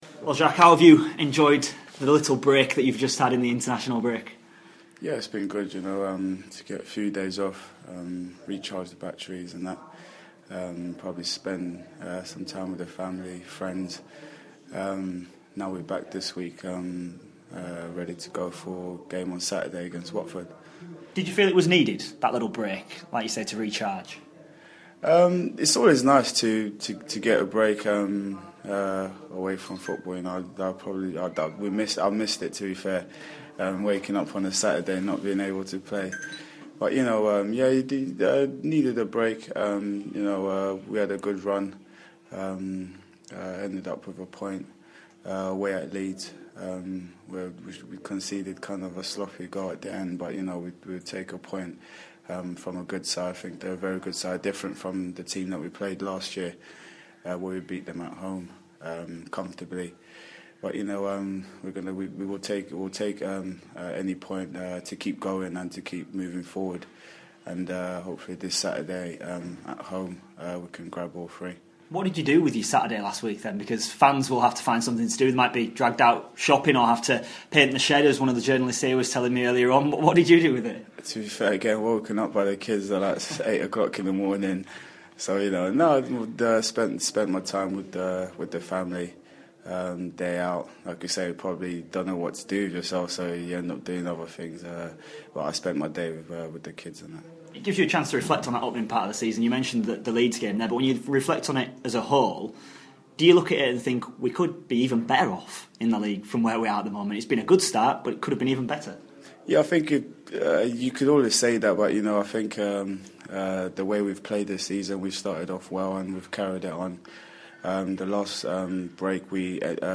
INTERVIEW: Sheffield Wednesday midfielder Jacques Maghoma has been looking ahead of the Owls game with Watford.